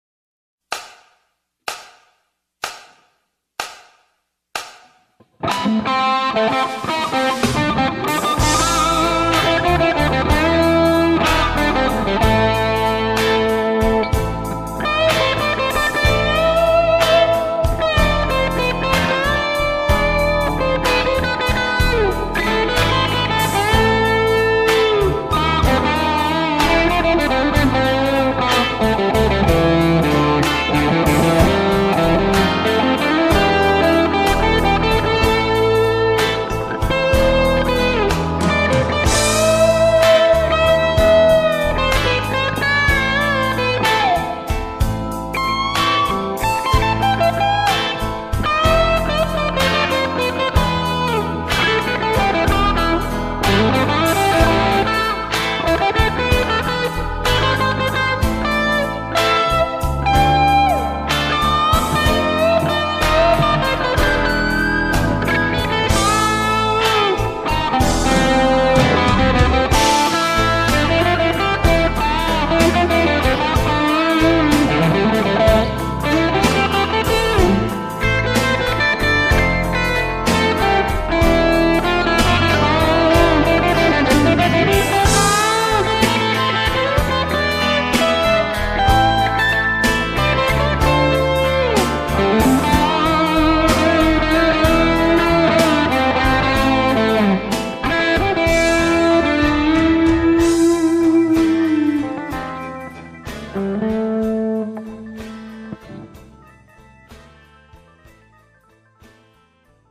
- kun osallistut, soita roots-soolo annetun taustan päälle ja pistä linkki tähän threadiin
Hyvä soundi ja tunnelmallista vääntöä. Vähän hätäisen oloinen paikoin.
hyvää ideaa tiukalla otteella